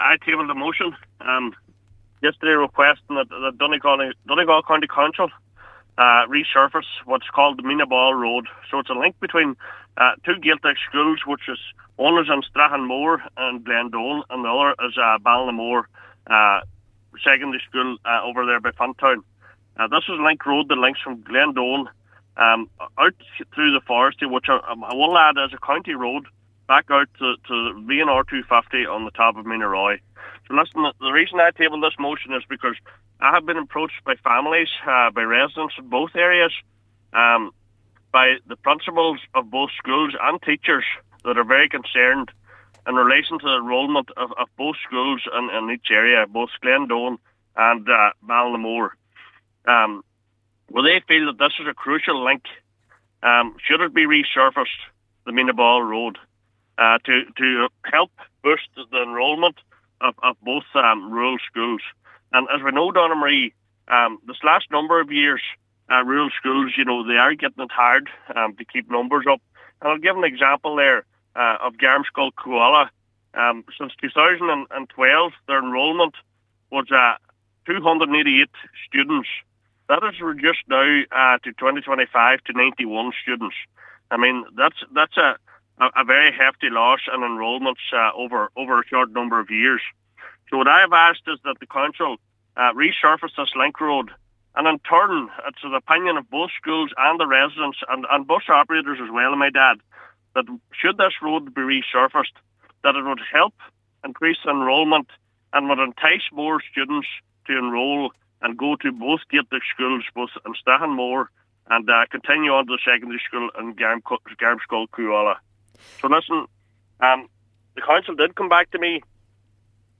Councillor Donal Mandy Kelly says the wider picture needs to be considered in terms of the Irish language: